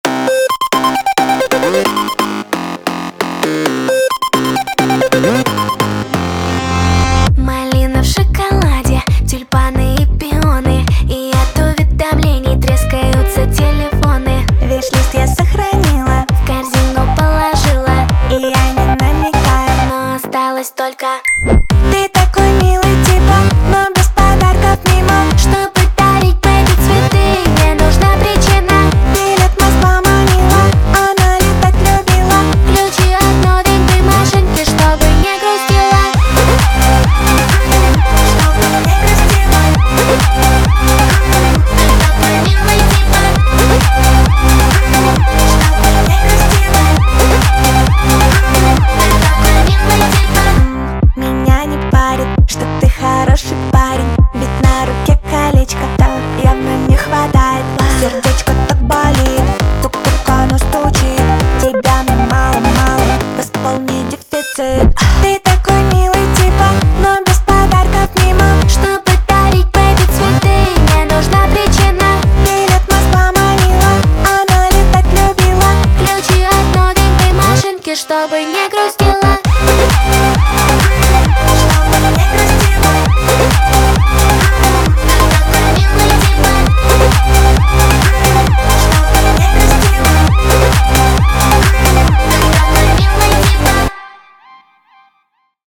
Качество: 320 kbps, stereo
Русские поп песни, 2026, Русские треки